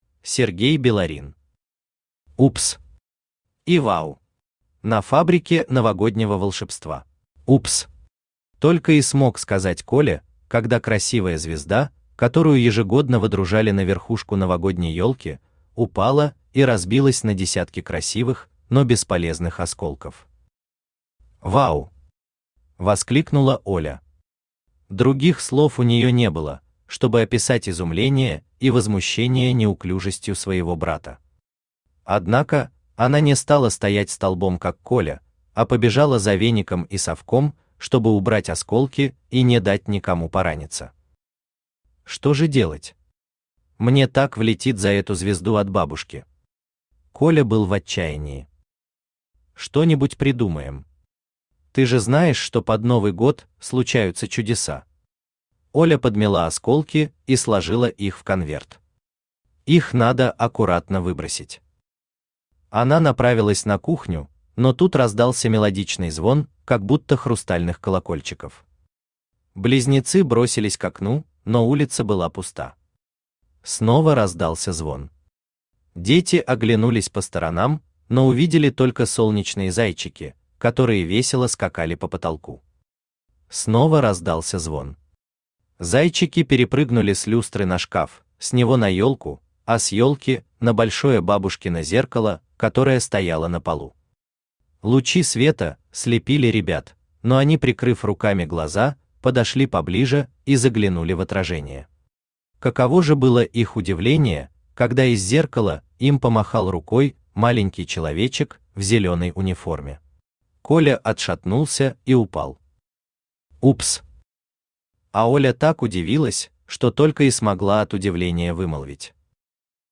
Aудиокнига «Упс!» и «Вау!» на Фабрике Новогоднего Волшебства Автор Сергей Биларин Читает аудиокнигу Авточтец ЛитРес.